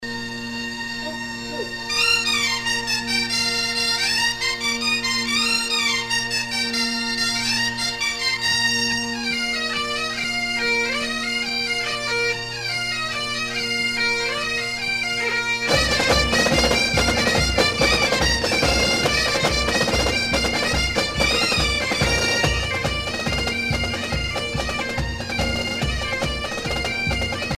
danse : gavotte bretonne
Pièce musicale éditée